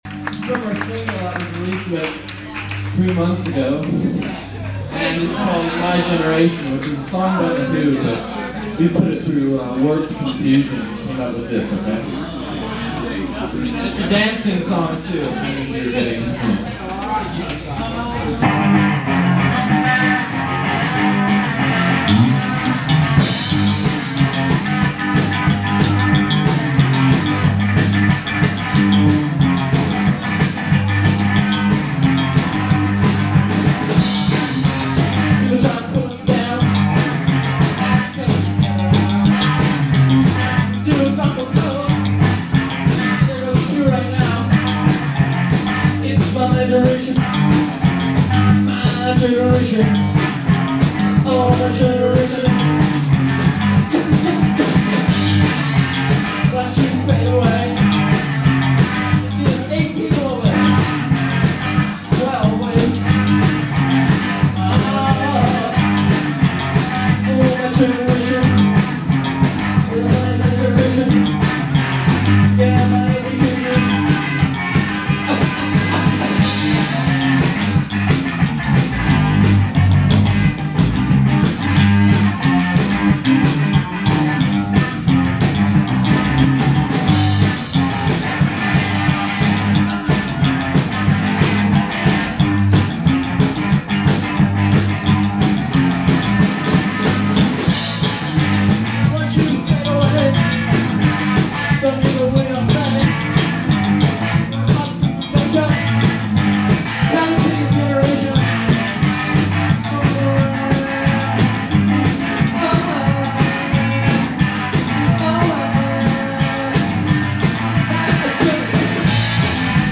Source : Cassette -> WAV/FLAC/MP3